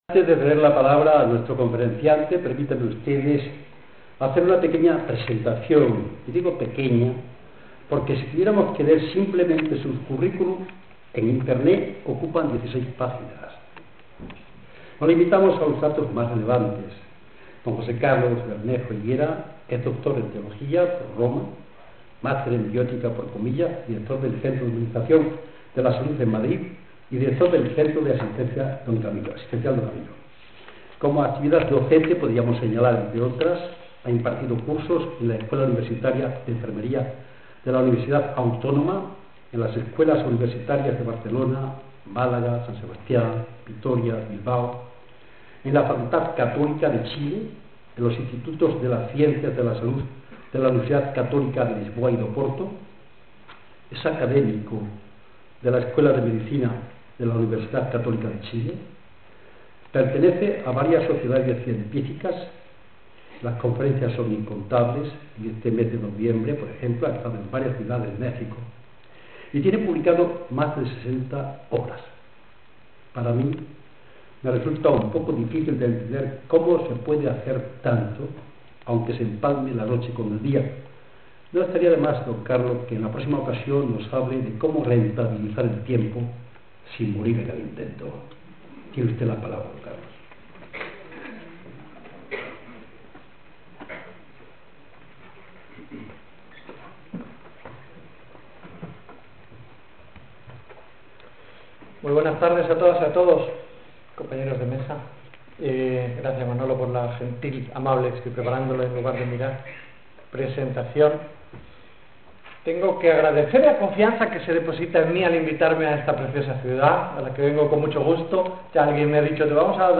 Charla